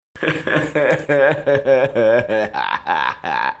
Play, download and share Vieze lach 1 original sound button!!!!
vieze-lach-1.mp3